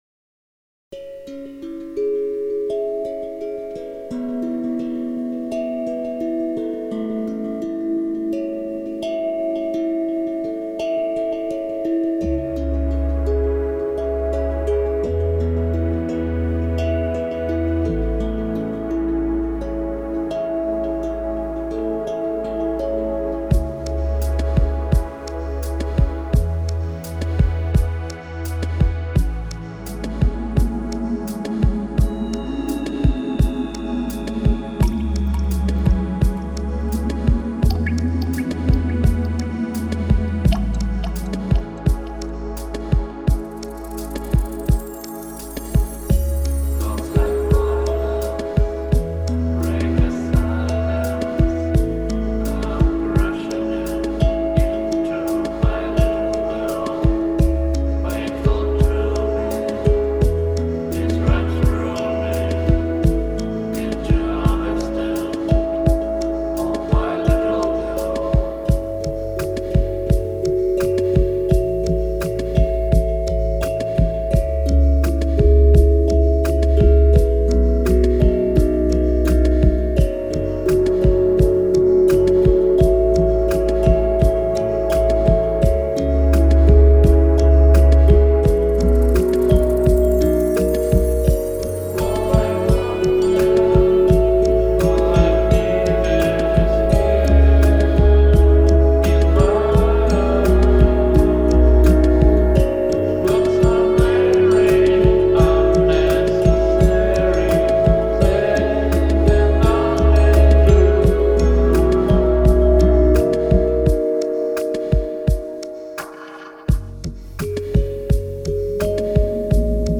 Chillout-кавер на известную песню. Пока ещё в разработке.